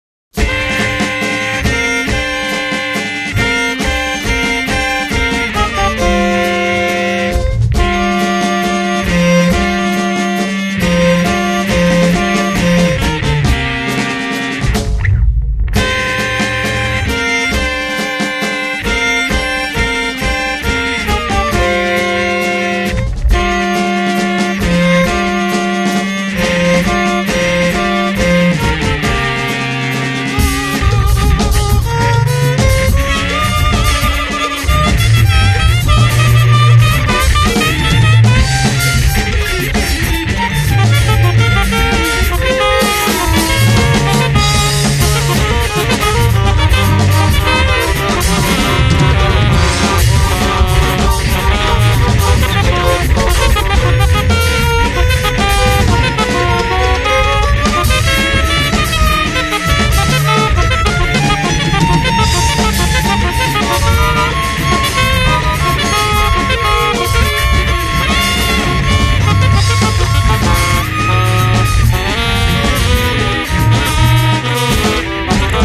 guitar and drums
avantjazz saxophonist